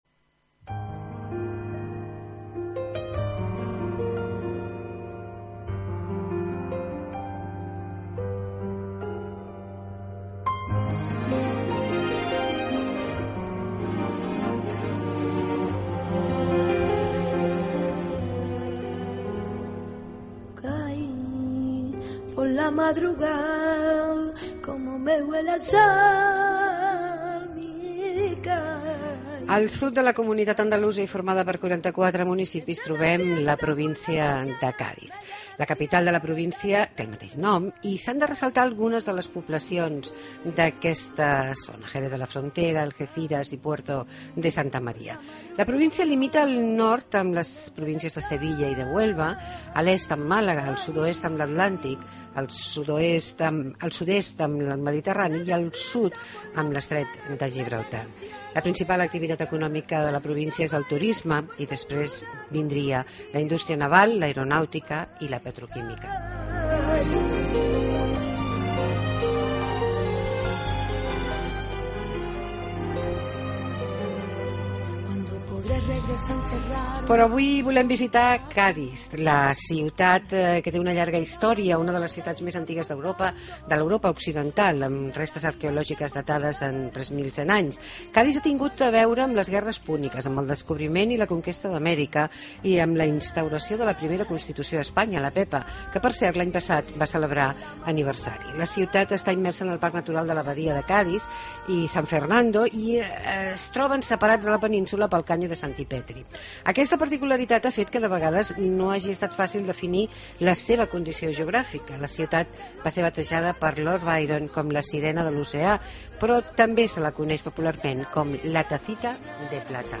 Entrevista a Bruno García, delegado de Turismo de Cádiz